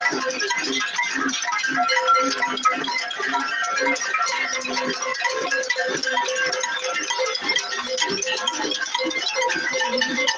Belle casserolade bien mélodieuse 🎶 à Azay-le-rideau en ce moment, pour accueillir le ministre de la culture !